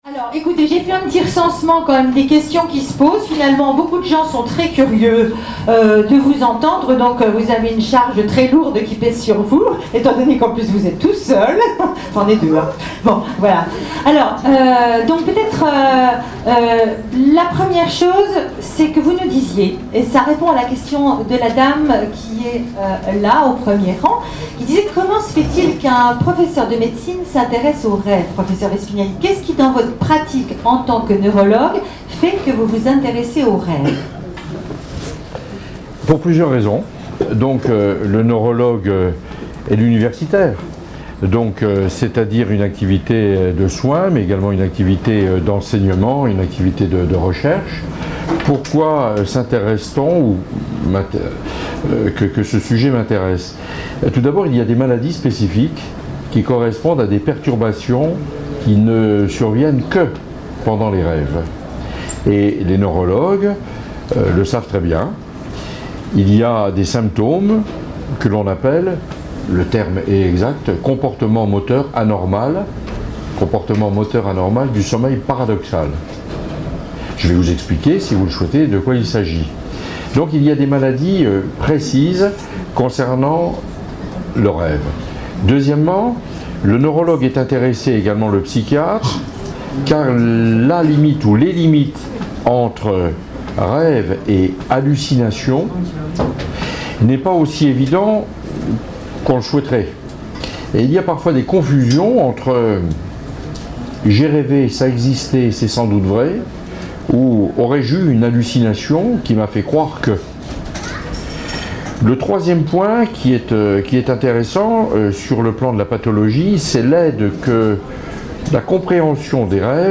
Forum santé à Nancy, "Le rêve et ses secrets "